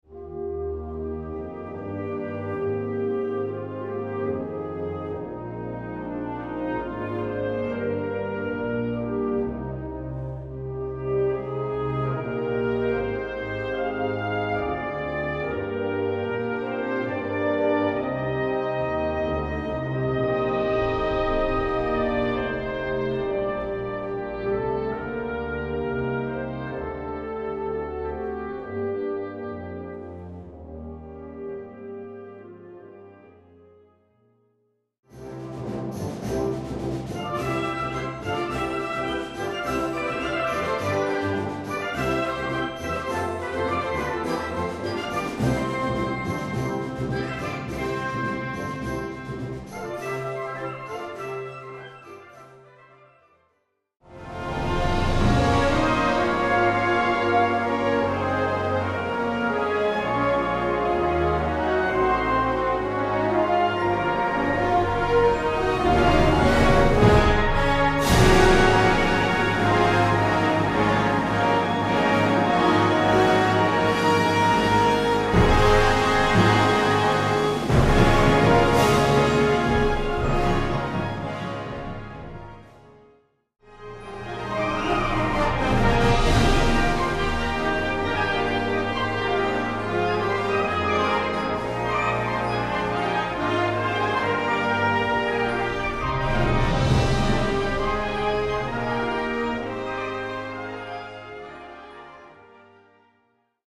Concert Band ou Harmonie